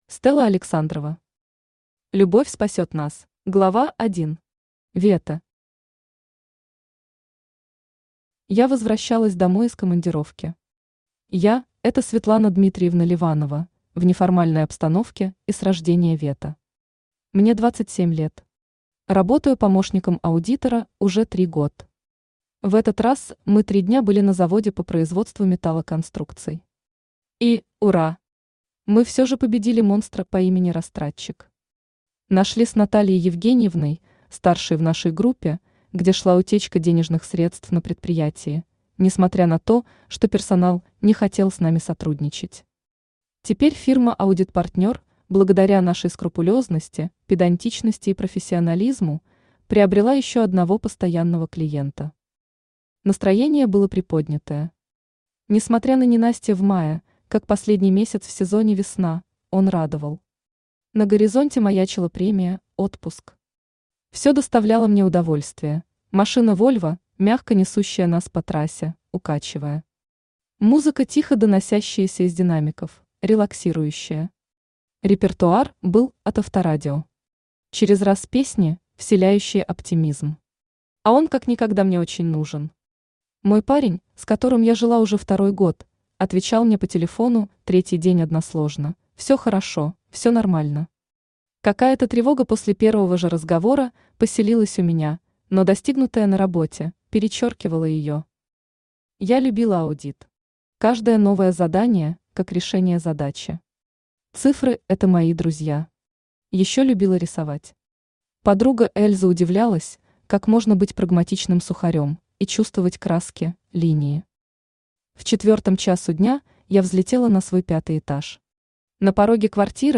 Аудиокнига Любовь спасет нас | Библиотека аудиокниг
Aудиокнига Любовь спасет нас Автор Стелла Александрова Читает аудиокнигу Авточтец ЛитРес.